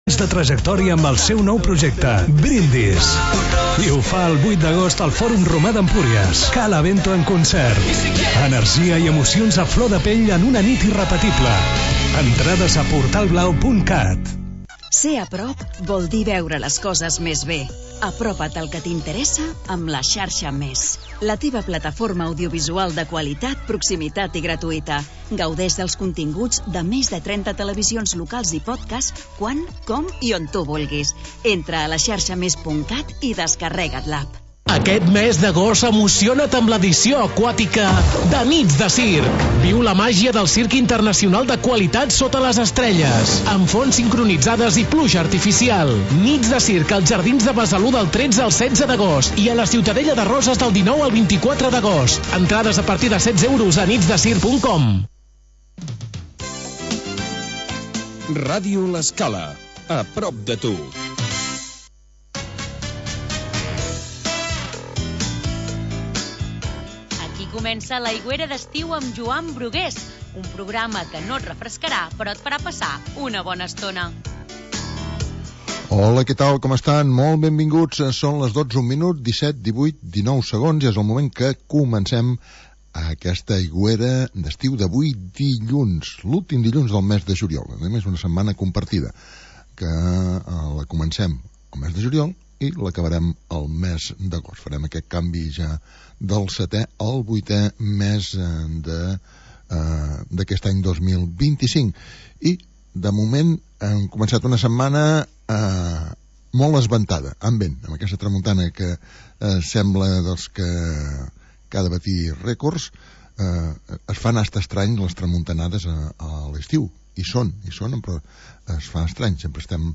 Magazín musical